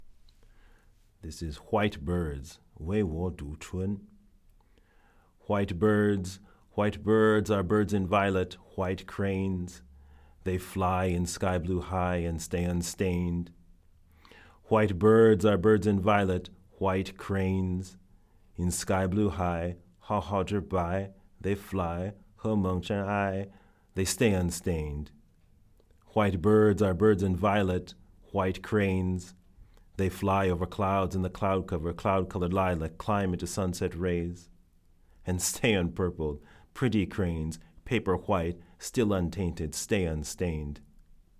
This year, I borrowed a small Japanese room and performed my poems.